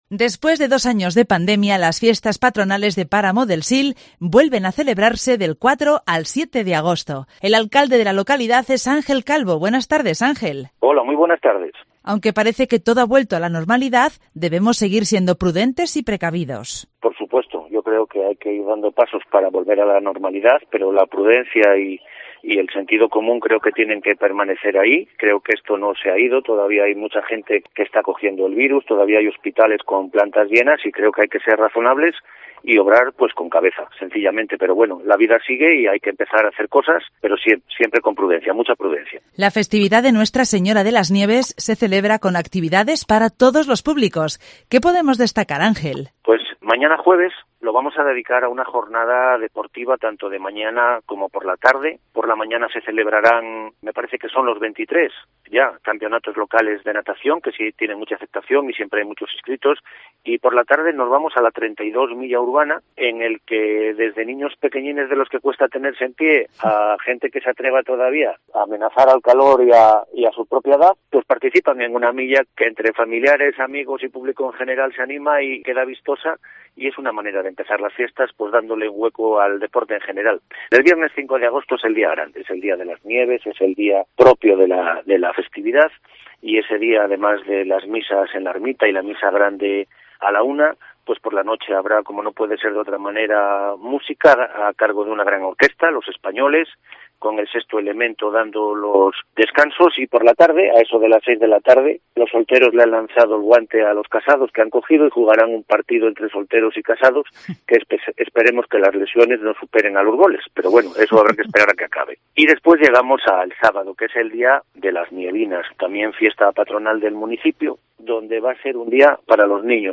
Hoy en Cope León visitamos Páramo del Sil. Ángel Calvo, el alcalde de la localidad, explica cuáles son las actividades programadas para celebrar las fiestas patronales en honor a la Virgen de Las Nieves, que se desarrollan del 4 al 7 de agosto. Aunque lo peor de la pandemia ha pasado, el alcalde recuerda en primer lugar que debemos seguir siendo prudentes y no bajar la guardia, y que a pesar de que el presupuesto del Ayuntamiento es menor, se ha hecho un esfuerzo para que las fiestas sigan estando a la altura con actividades gratuitas para todos los públicos.